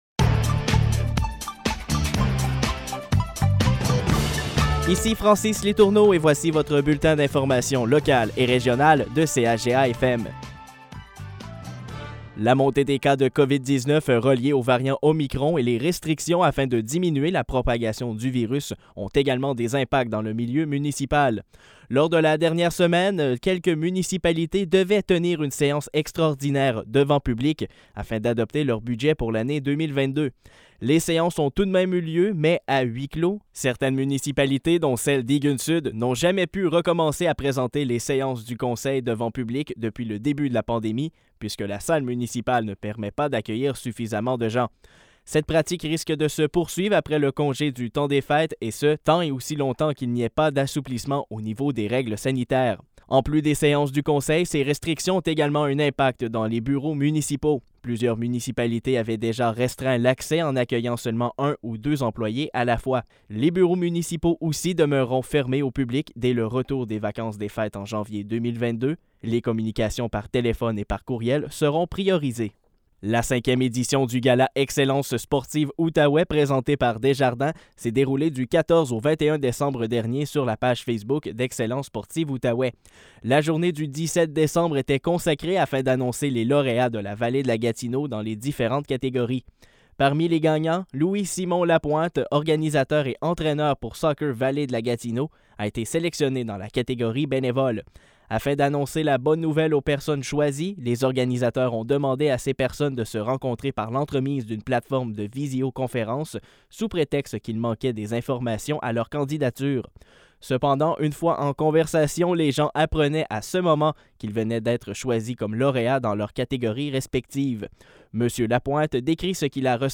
Nouvelles locales - 24 décembre 2021 - 15 h